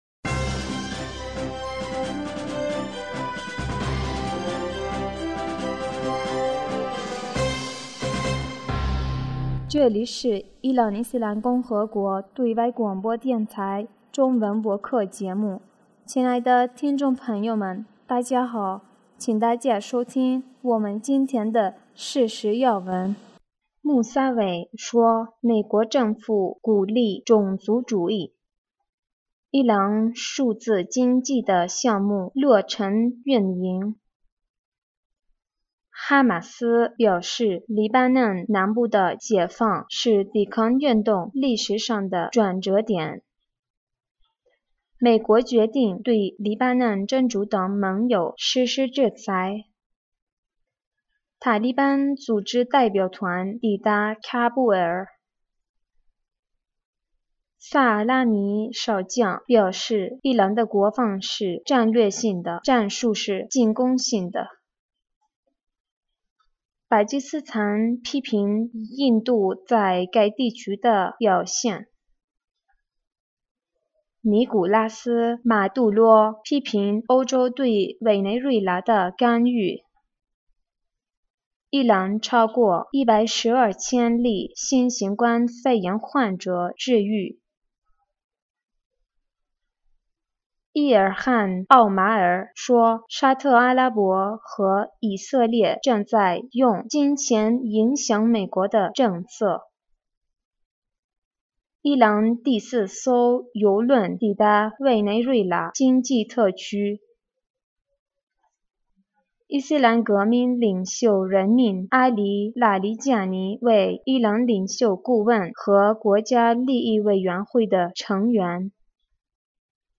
2020年5月28日 新闻